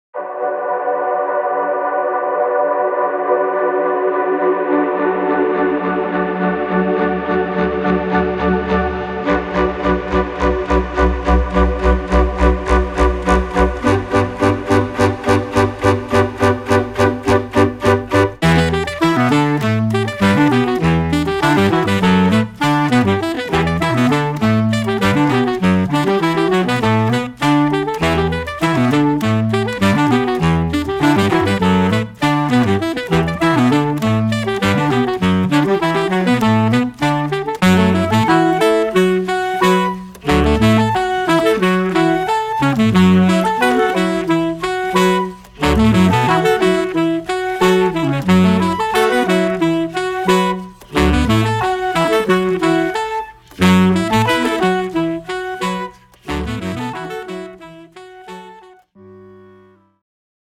alto and tenor saxophone